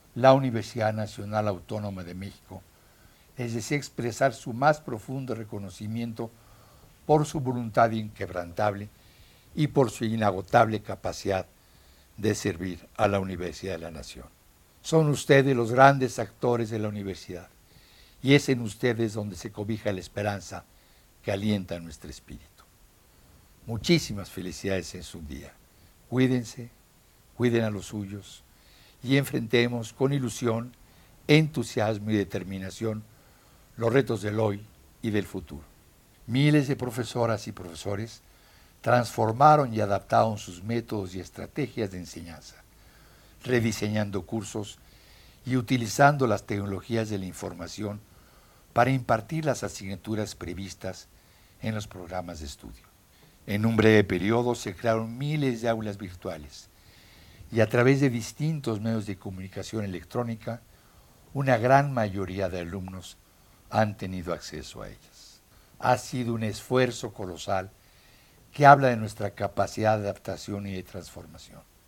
• El rector dirigió un mensaje con motivo del Día del Maestro